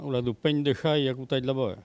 Patois - Archive